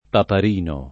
papà
papà [ pap #+ ] s. m.